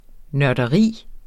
Udtale [ nɶɐ̯dəˈʁiˀ ]